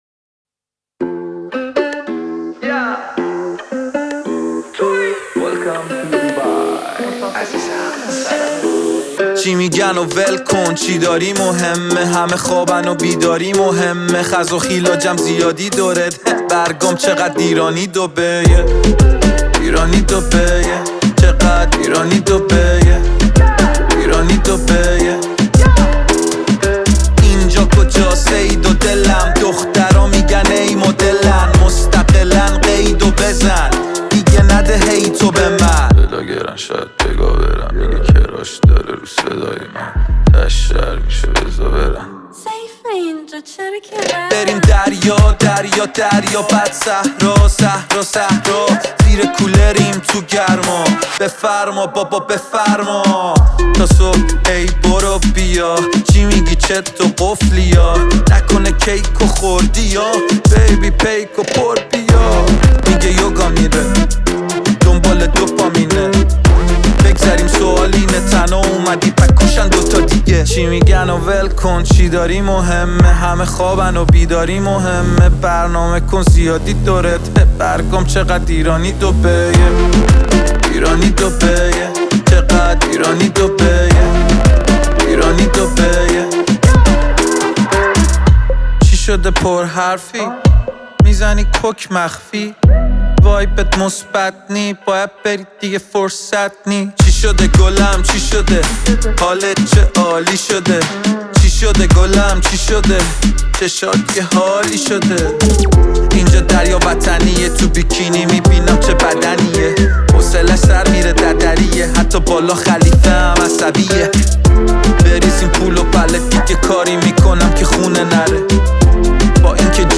پاپ رپ